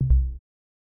viewer-leave.mp3